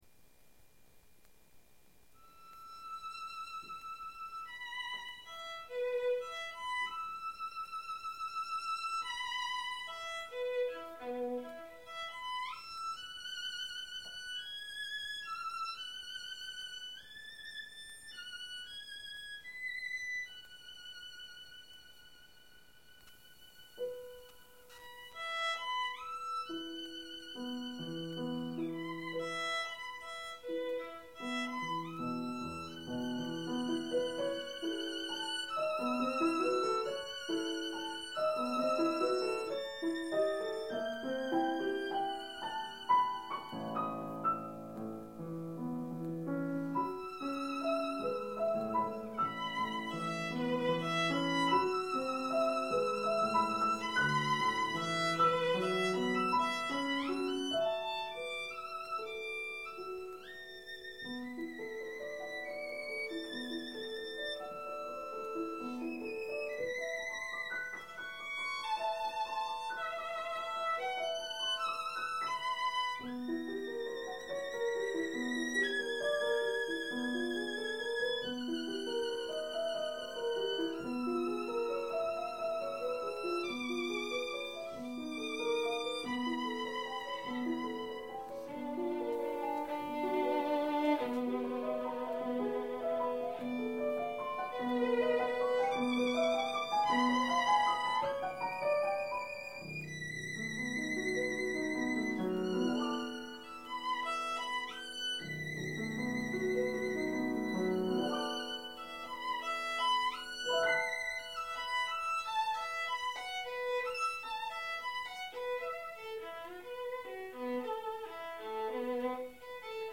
in a public concert at the Library of the Regensburg University. Ernest Bloch: Poème Mystique (1924) (Violin Sonata No. 2) recording of July 2009